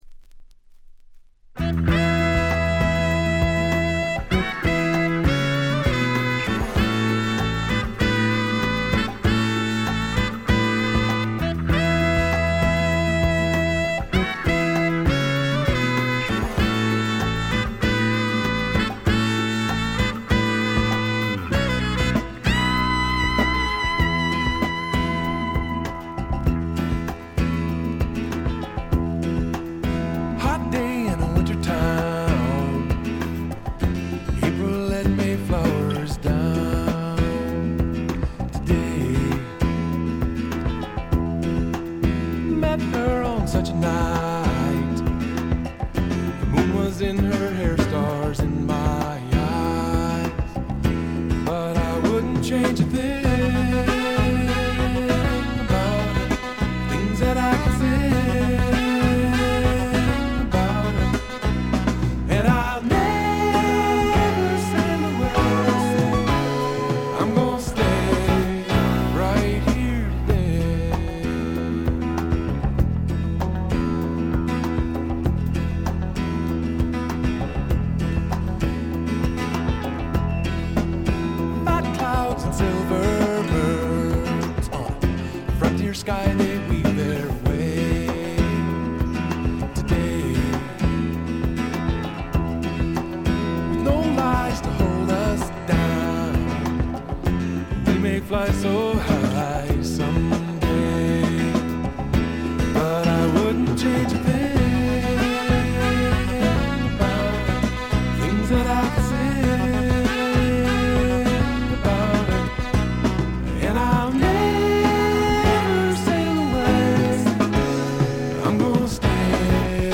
静音部で軽微なチリプチ。
メロウ系、AOR系シンガー・ソングライターのずばり名作！
試聴曲は現品からの取り込み音源です。